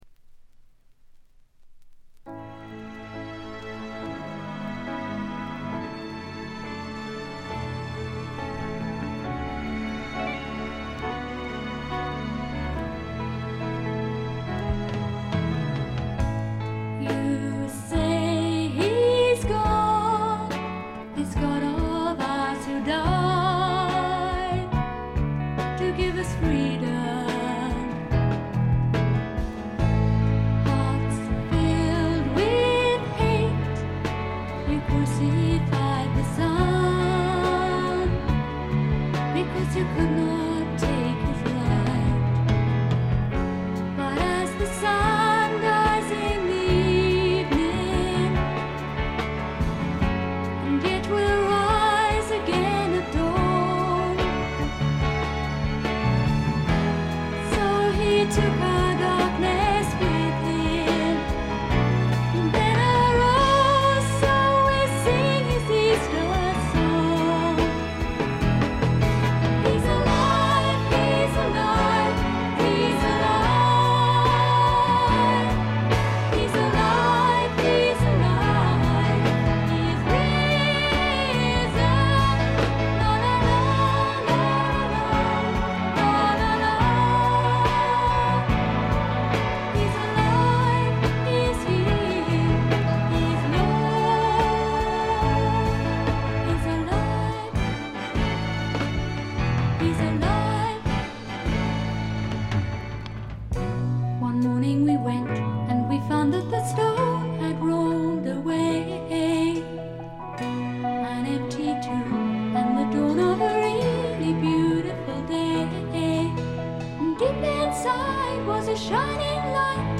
部分試聴ですが静音部での軽微なチリプチ程度。
古くからクリスチャン・ミュージック系英国フィメールフォークの名盤として有名な作品ですね。
試聴曲は現品からの取り込み音源です。